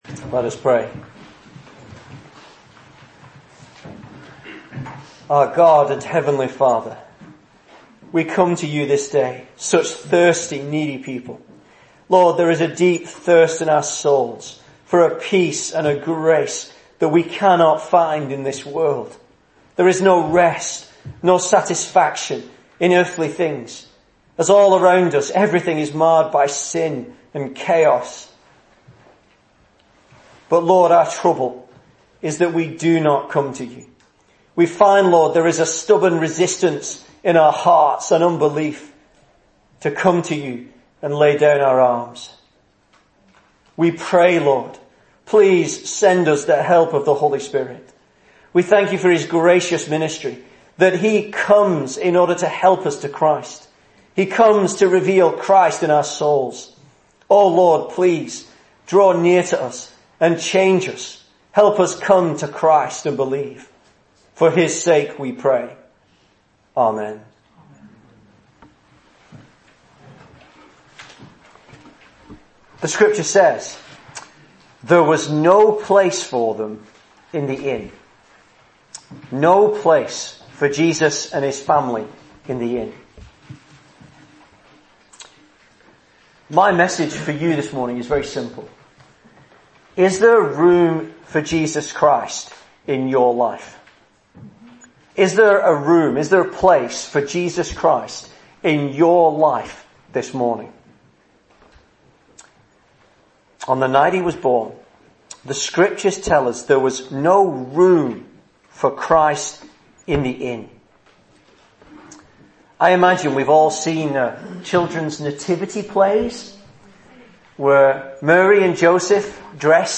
Christmas Sermons